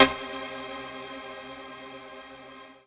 HOUSE117.wav